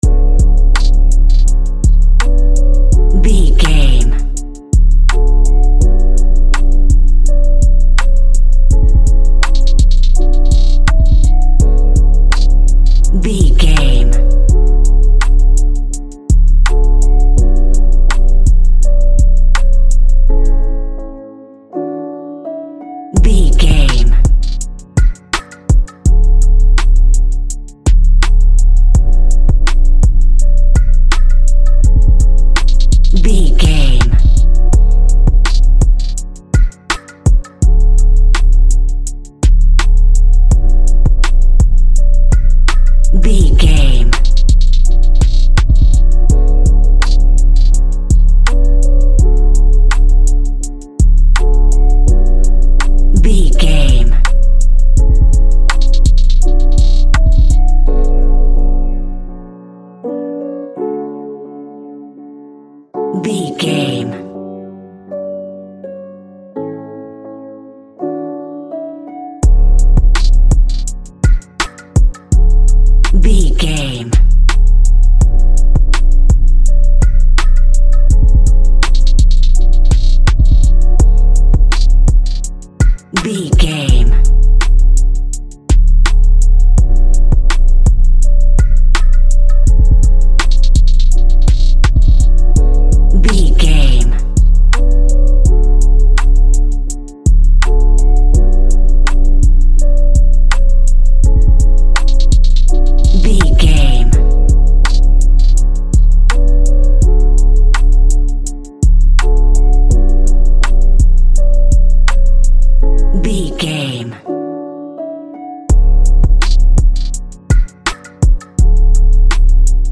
Ionian/Major
drums
smooth
calm
mellow